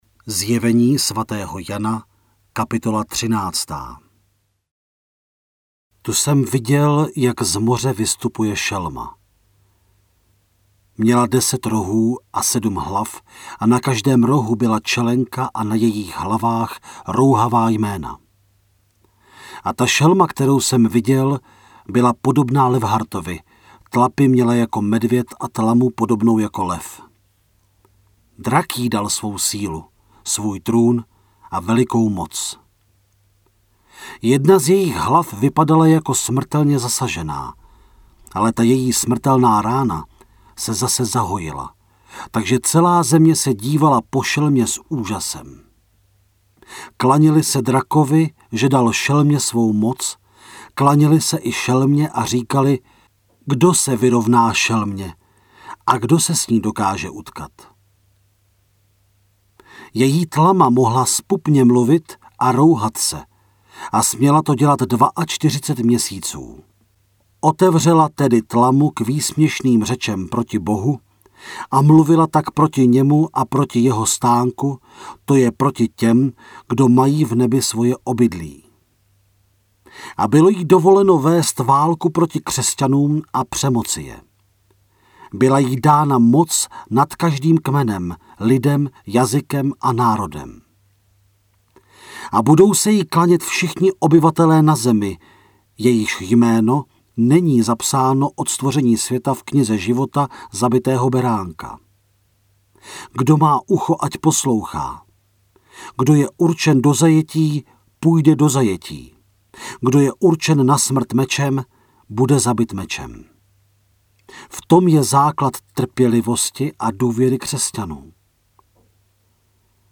Načtená kniha je rozdělená na 22 částí, které odpovídají dělení kapitol.
Stahujte celé zde (75MB):  ZJEVENÍ SVATÉHO JANA – audiokniha, Studio Vox 2018